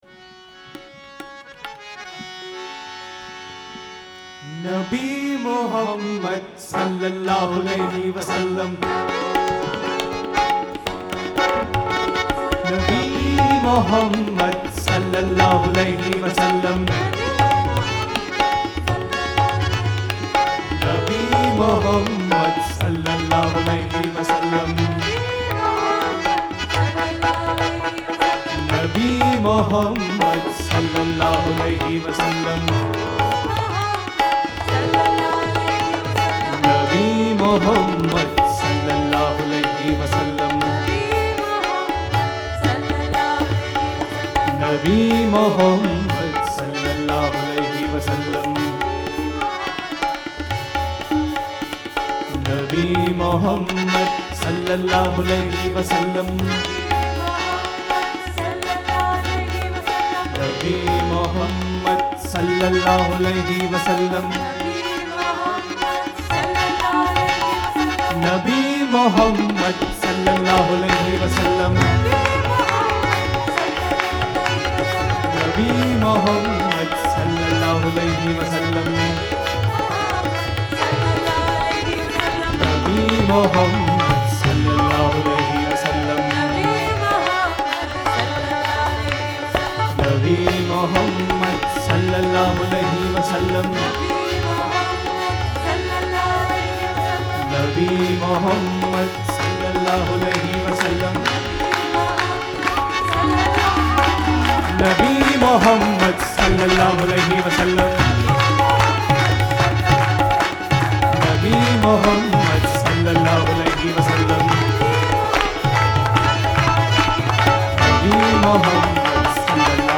1. Devotional Songs
~ Minor (Natabhairavi)
8 Beat / Keherwa / Adi
Medium Fast
4 Pancham / F
1 Pancham / C